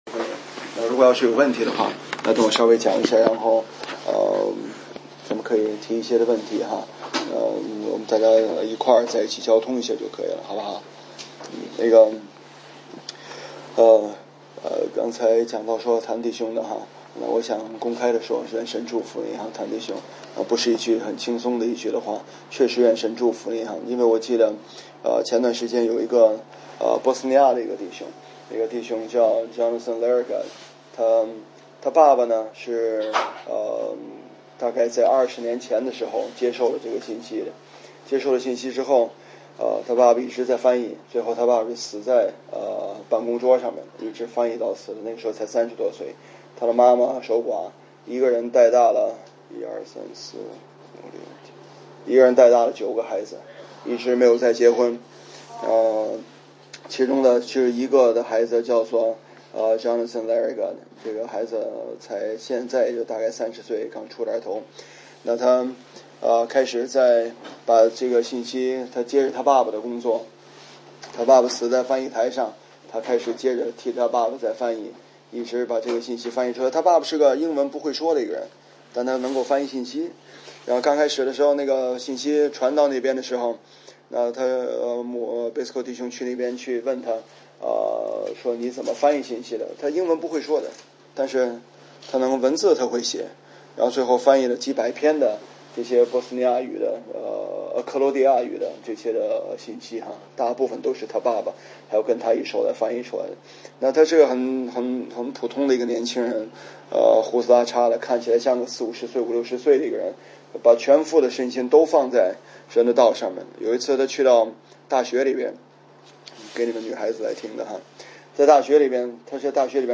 中国讲道录音